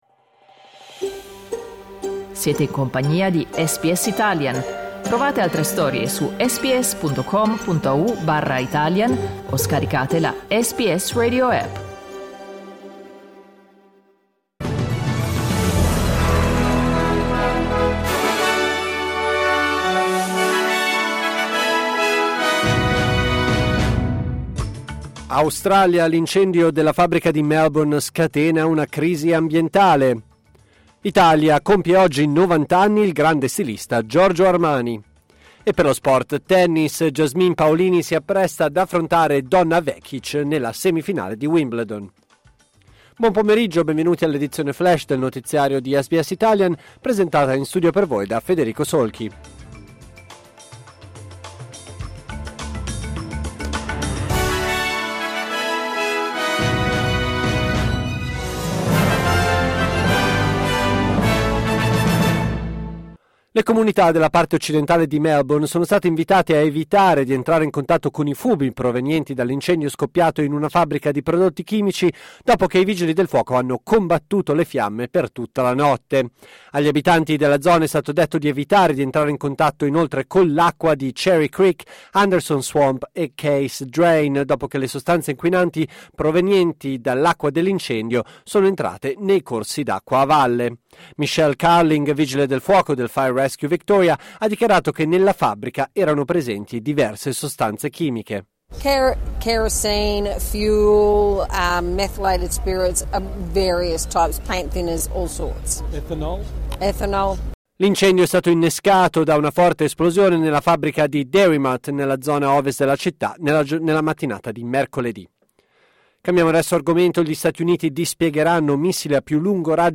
News flash giovedì 11 luglio 2024
L’aggiornamento delle notizie di SBS Italian.